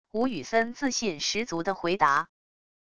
吴宇森自信十足的回答wav音频